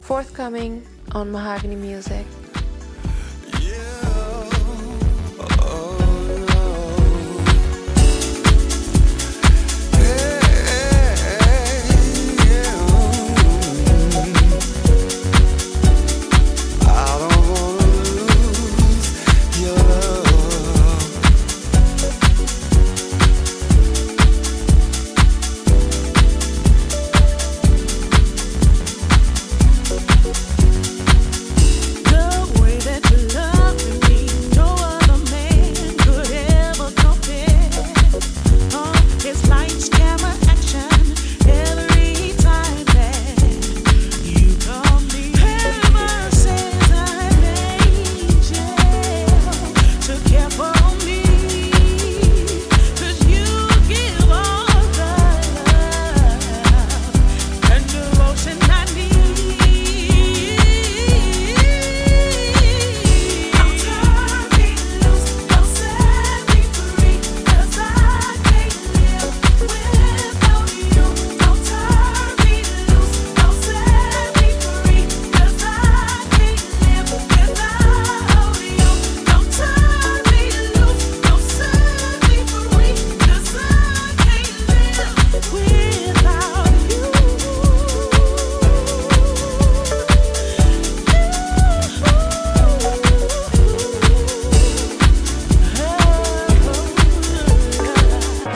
シルキー＆グルーヴィーに盛り上がる極上の男女混声ヴォーカル・ハウスに仕上がっています！